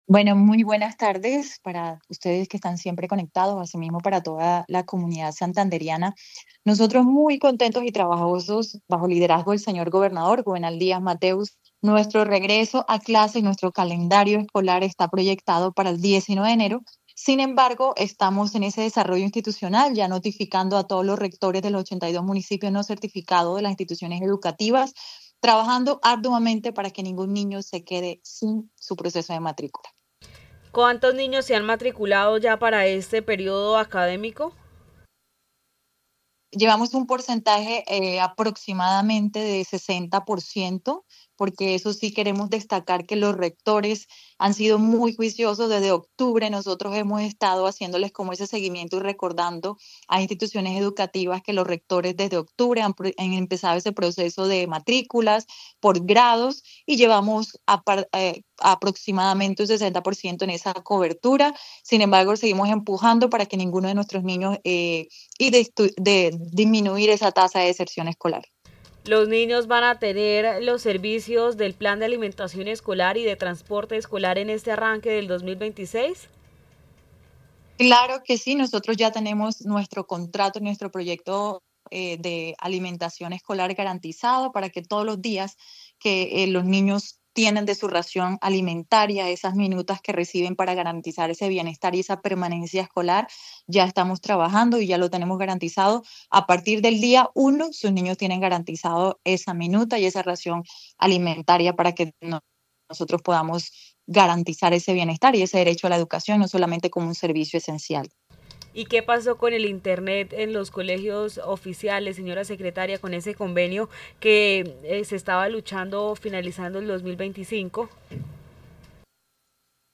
Karina Araujo, secretaria de educación de Santander